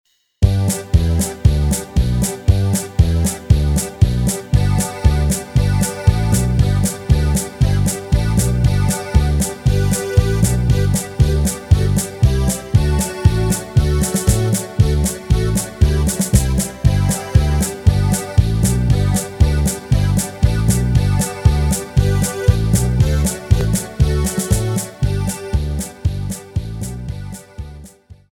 Rubrika: Národní, lidové, dechovka
- polka
Karaoke
Gdur